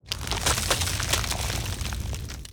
Ice Wall 2.wav